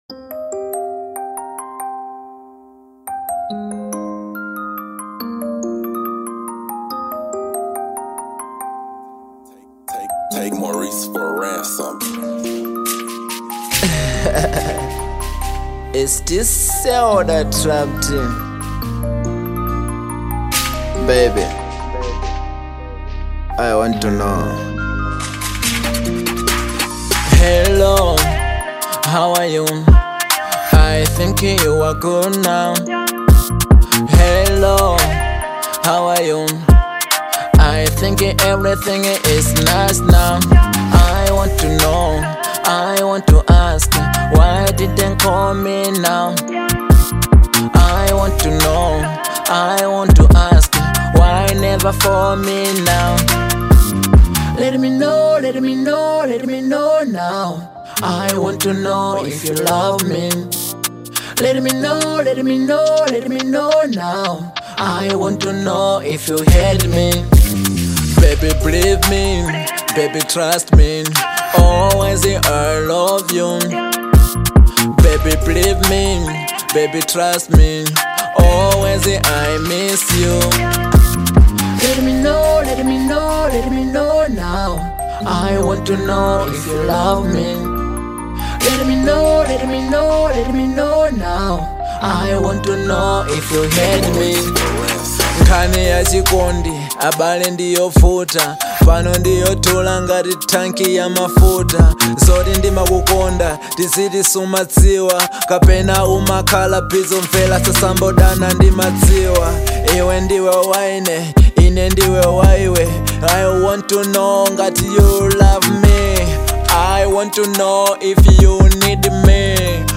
Genre : Drill/Hiphop